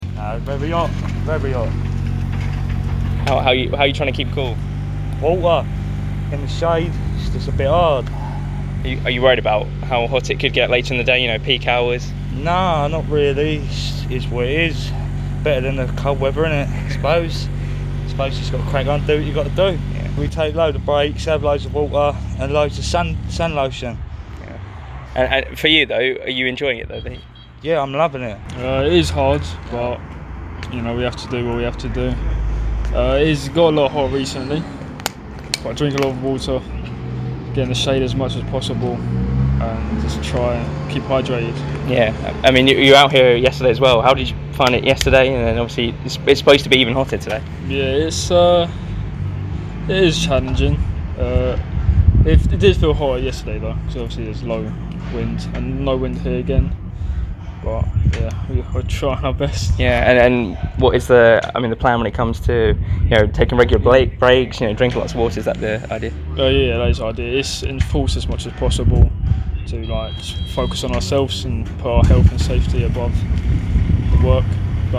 Listen: Workmen in Gillingham have been speaking about coping with the heat - 19/07/2022